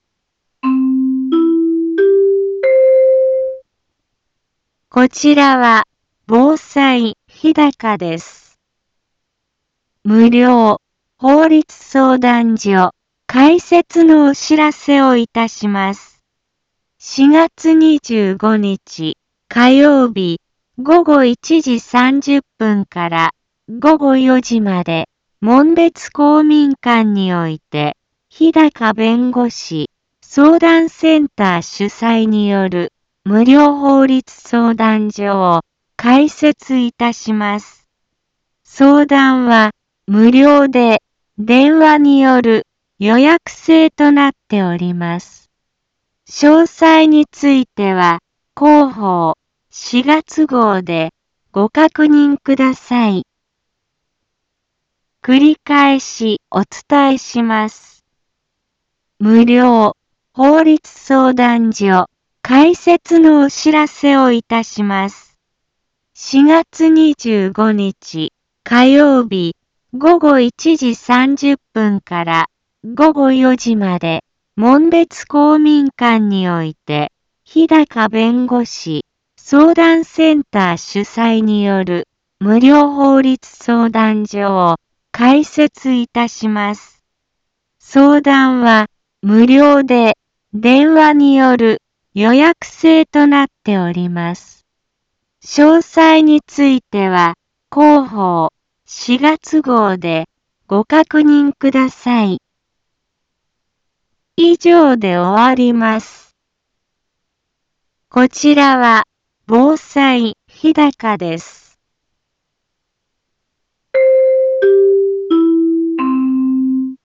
一般放送情報
Back Home 一般放送情報 音声放送 再生 一般放送情報 登録日時：2023-04-18 15:04:06 タイトル：無料法律相談会のお知らせ インフォメーション：こちらは防災日高です。